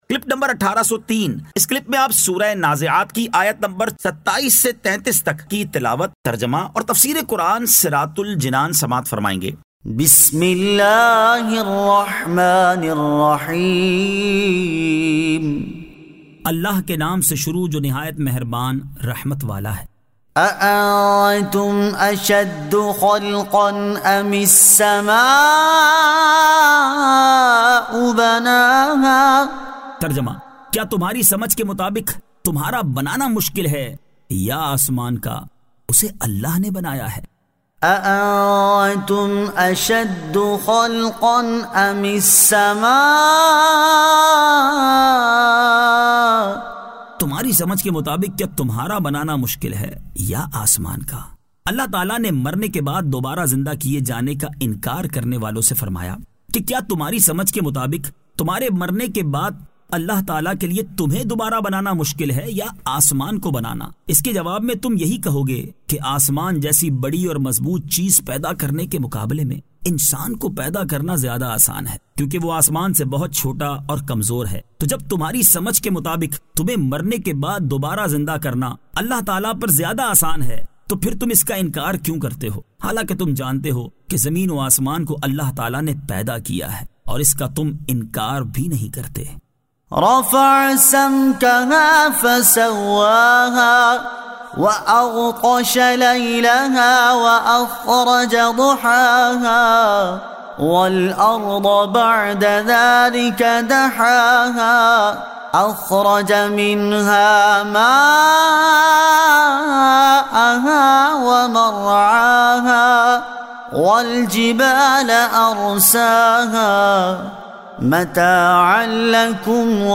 Surah An-Nazi'at 27 To 33 Tilawat , Tarjama , Tafseer